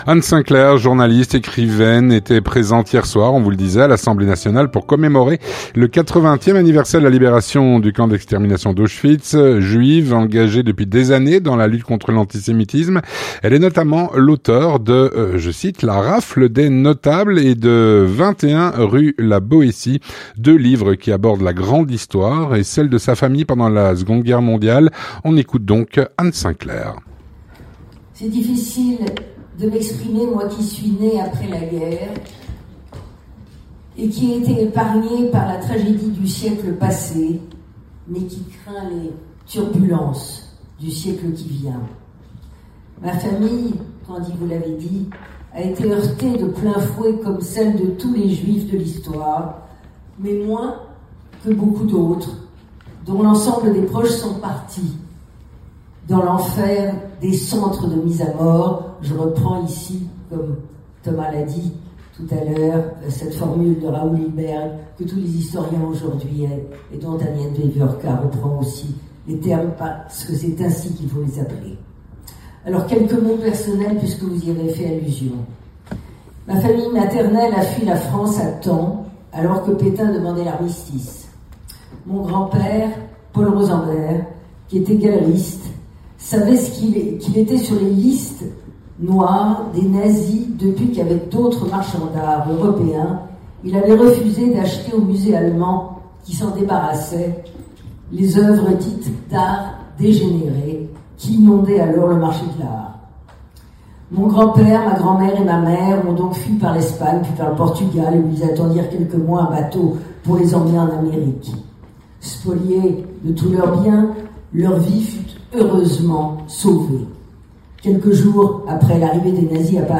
Témoignage - Anne Sainclair témoigne lors du 80ème anniversaire de la libération du camp d'Auschwitz. (28/01/2025)
Anne Sinclair journaliste, écrivaine, était présente hier soir à l’Assemblée Nationale Française pour commémorer le 80ème anniversaire de la libération d’Auschwitz.
On écoute son discours prononcé à cette occasion.